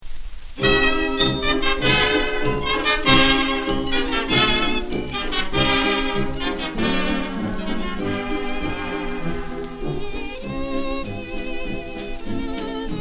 And here is the hiss-reduced result:
dehissed.mp3